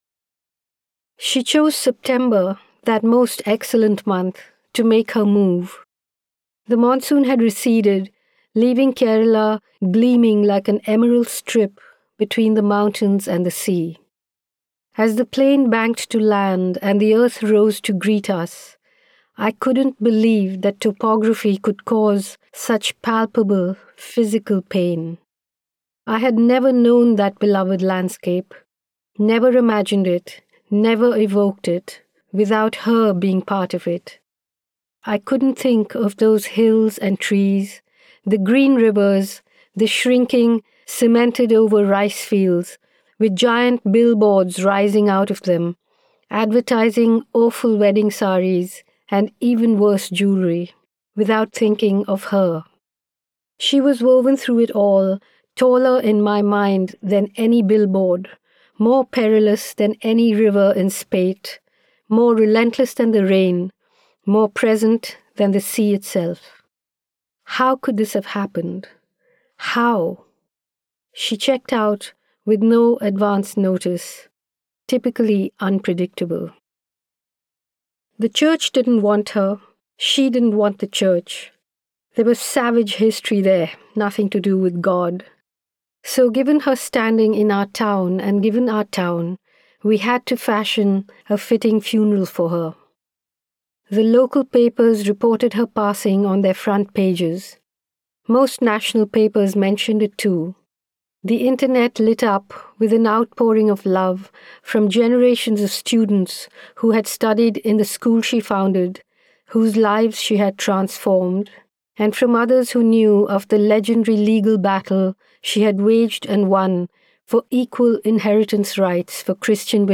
Audiobook sample